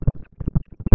Nota concedida por el Presidente Dr. Jorge Batlle Ibáñez, a la Cadena